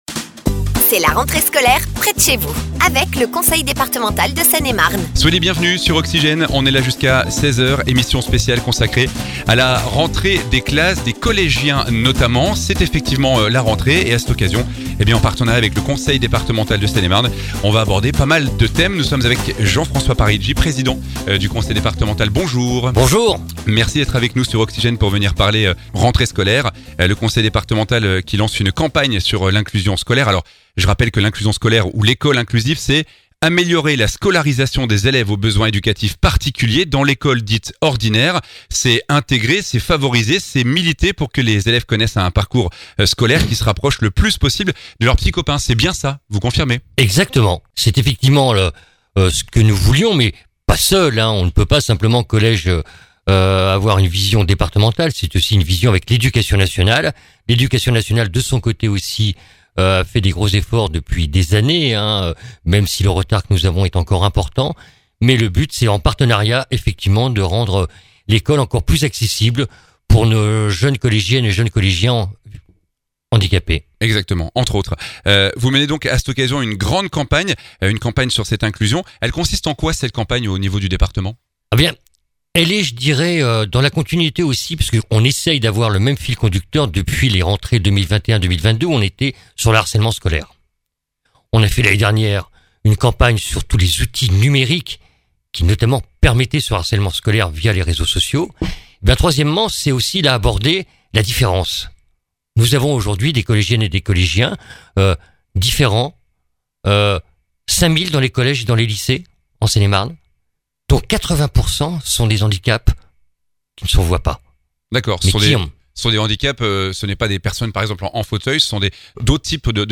Réécoutez les interviews de l'émission spéciale C'est la rentrée près de chez vous avec le Conseil Départemental de Seine et Marne.
Dans ce podcast : Jean-François Parigi, président du conseil départemental de Seine et Marne.
Xavier Vanderbise vice-président du conseil départemental de Seine et Marne.